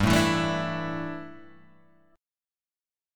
Dsus2/G chord